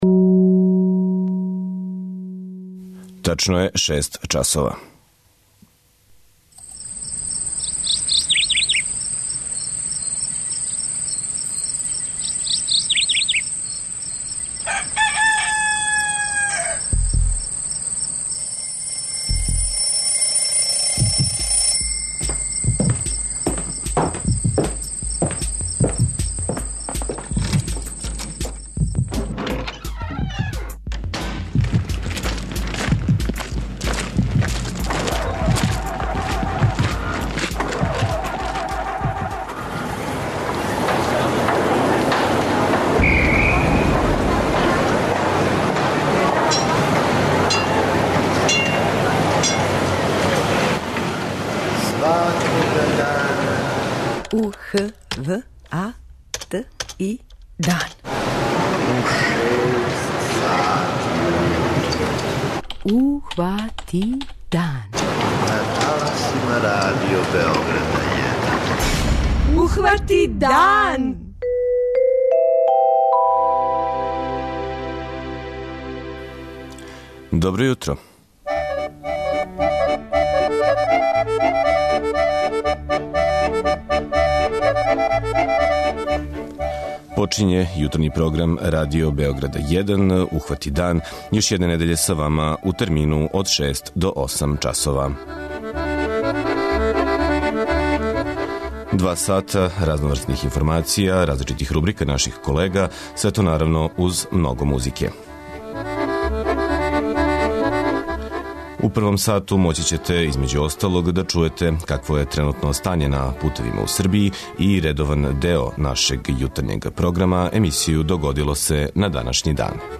преузми : 57.29 MB Ухвати дан Autor: Група аутора Јутарњи програм Радио Београда 1!